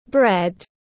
Bánh mì tiếng anh gọi là bread, phiên âm tiếng anh đọc là /bred/
Bread /bred/